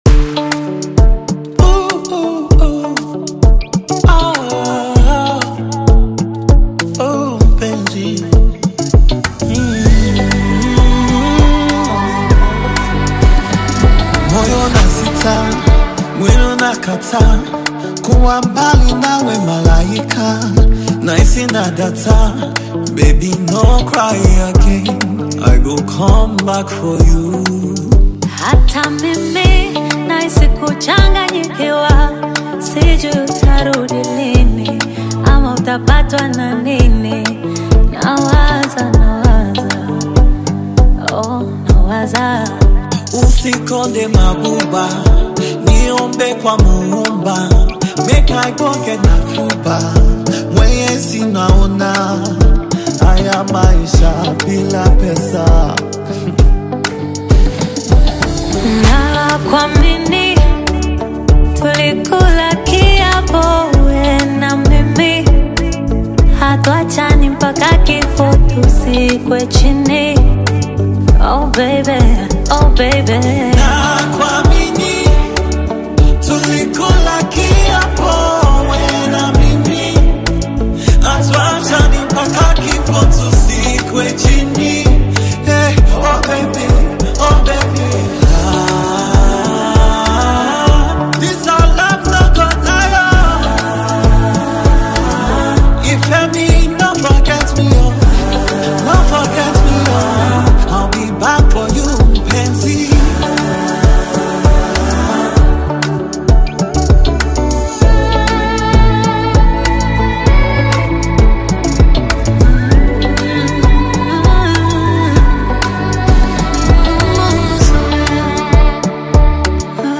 AfrobeatAudioNigerian Music
soulful Tanzanian Afro-Pop/Bongo Flava single